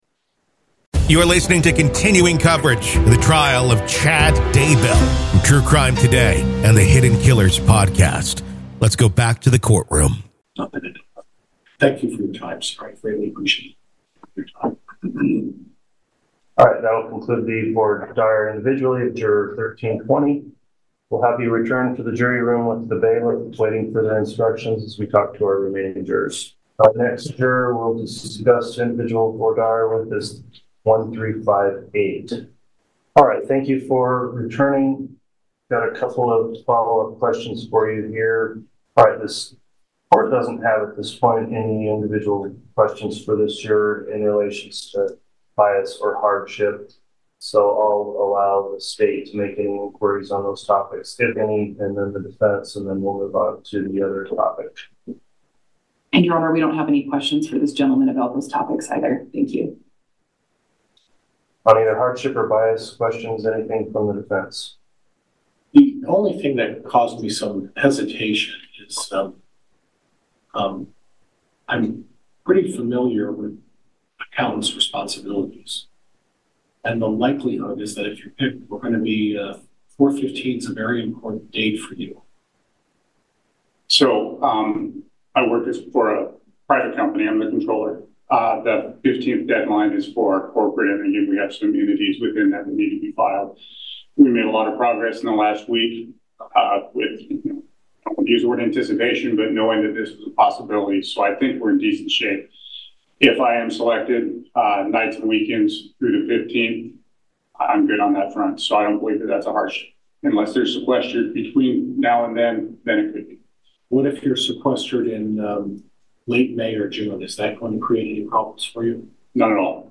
The Trial Of Lori Vallow Daybell | Full Courtroom Coverage / The Trial of Chad Daybell Jury Selection, Day 3 Part 3